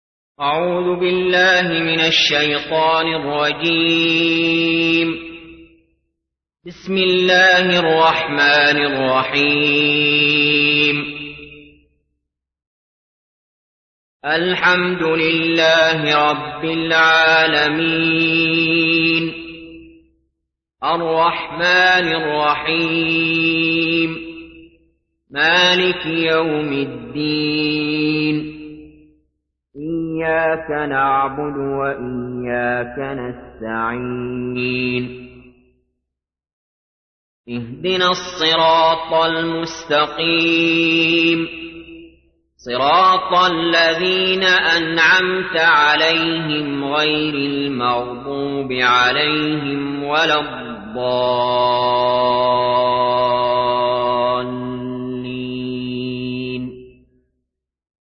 سورة الفاتحة / القارئ علي جابر / القرآن الكريم / موقع يا حسين